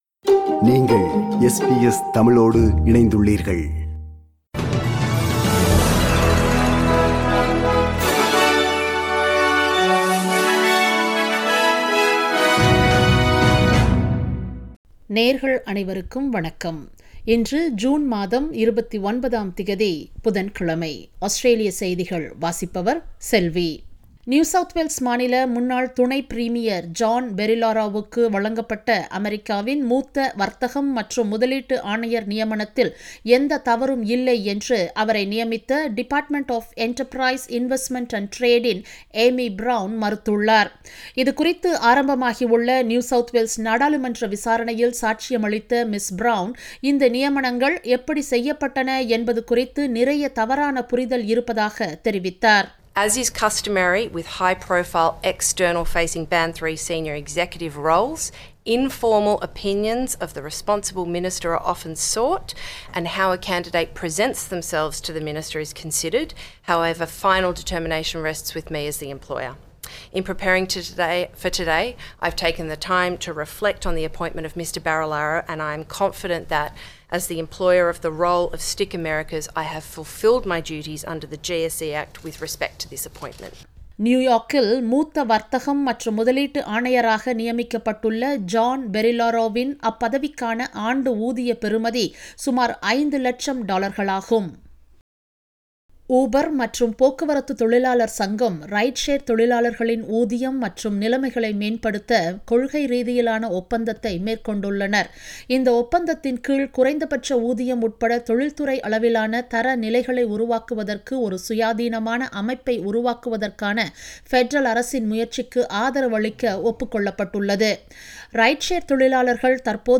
Australian news bulletin for Wednesday 29 June 2022.